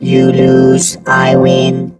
rick_kill_vo_07.wav